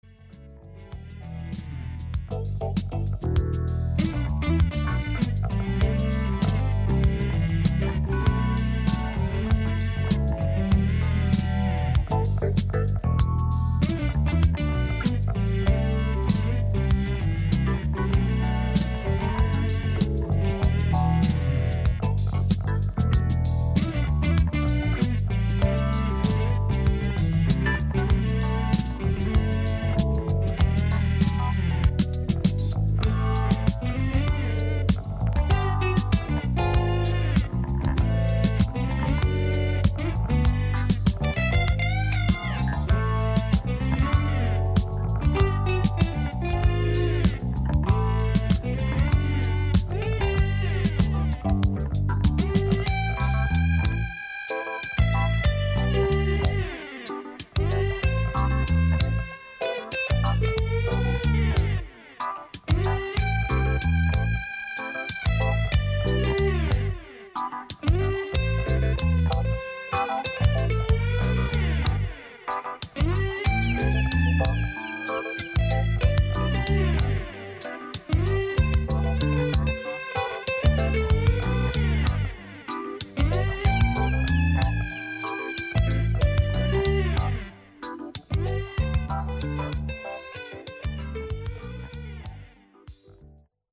Disco Funk Soul
ソウルフルで華のあるディスコ〜ファンク名盤！